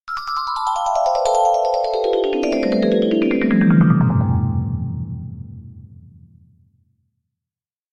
Звуки магии